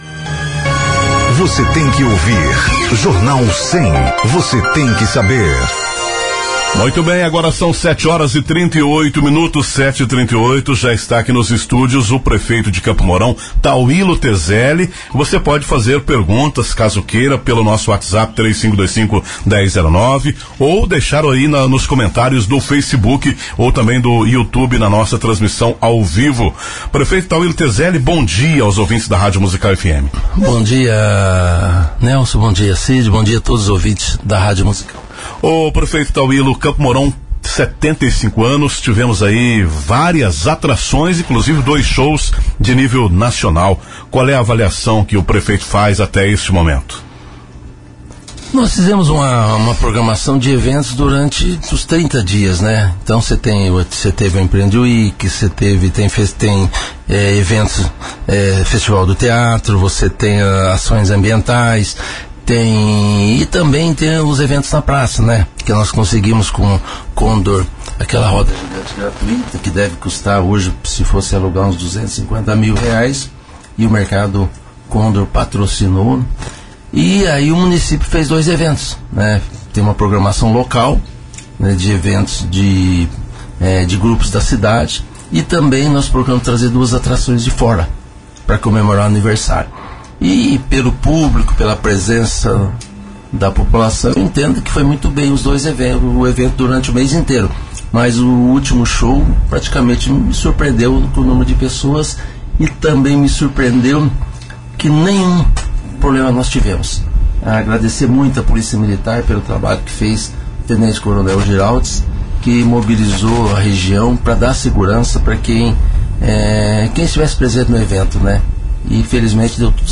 Nesta quarta-feira, dia 19, o prefeito de Campo Mourão, Tauillo Tezelli, foi o entrevistado da edição do Jornal 100, da Rádio Musical FM.
Festividades dos 75 anos do município, concurso público da prefeitura, obras realizadas, projetos em andamento e ainda a serem lançados, alguns dos temas da entrevista. Tezelli também respondeu questionamentos da população, que foram enviados via aplicativos mensageiros e redes sociais da emissora mourãoense.